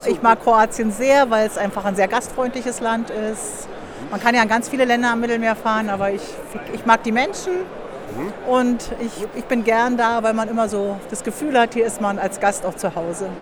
Tako nam je jedna posjetiteljica sajma rekla da je našu zemlju posjetila već jedno pet, šest puta i namjerava ponovno.